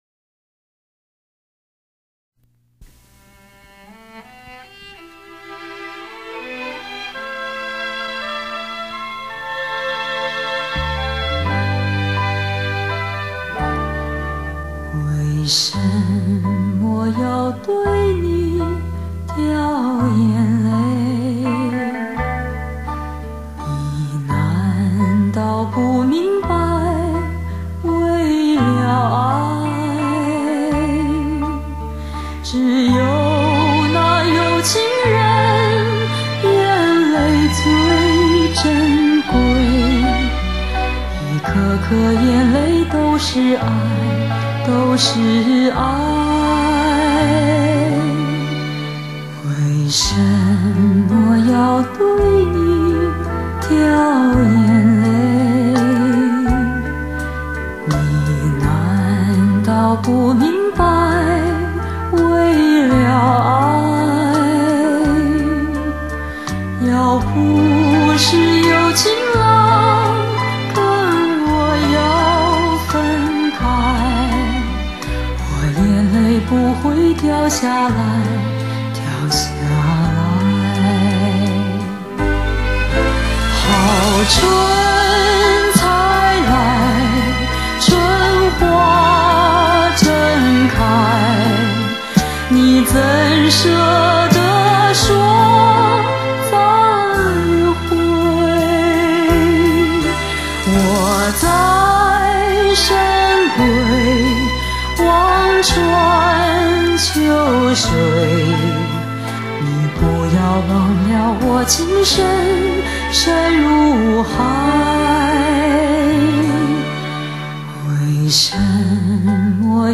很唯美，但不腻味，她的歌带有很深的忧伤，牵动着每个人的心肺......